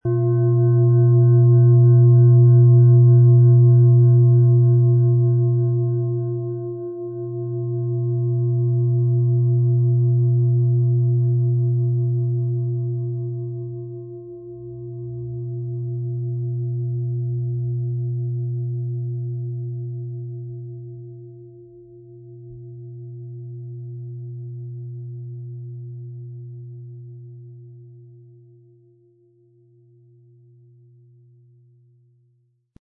Planetenton 1
Um den Originalton der Schale anzuhören, gehen Sie bitte zu unserer Klangaufnahme unter dem Produktbild.
Der passende Schlegel ist umsonst dabei, er lässt die Schale voll und harmonisch tönen.
MaterialBronze